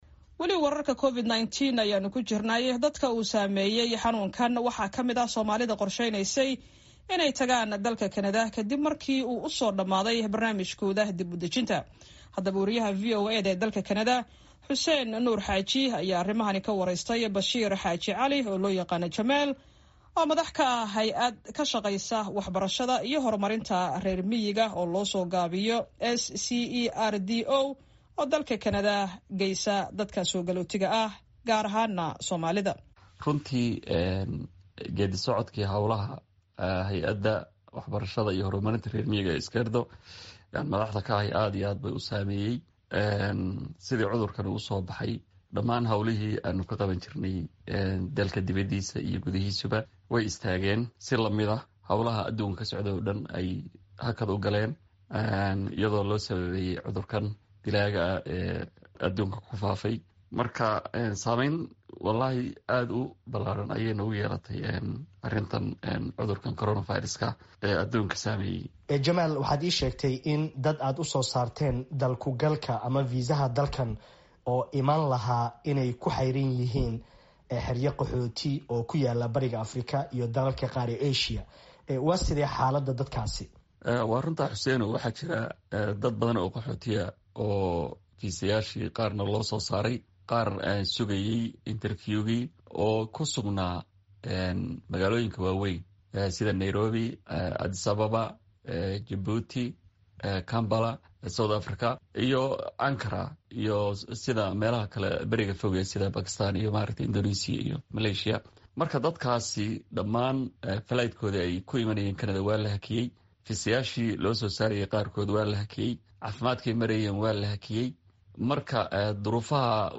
ayaa arrimahaasi ka wareystay